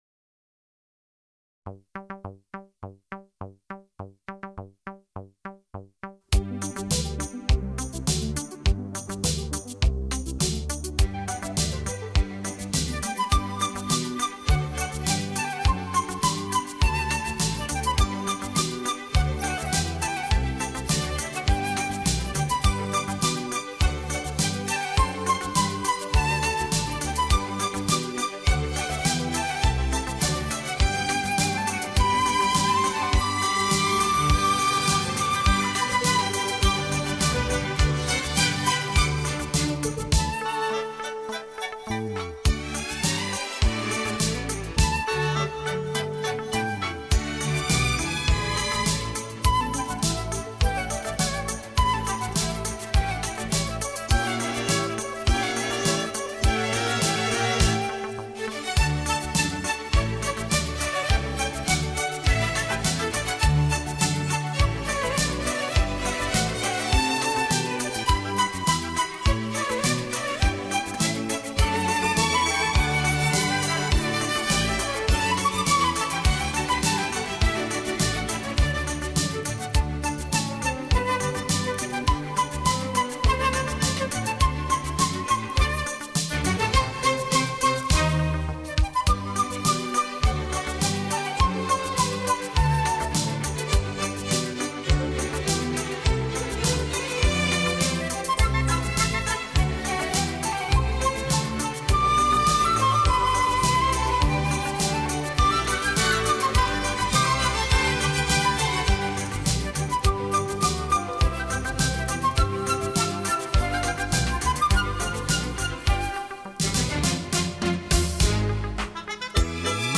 她演奏很多古典乐曲，但似乎并不是严格地再现，而是加入自己的很多理解，表现方法上也更贴近现代些。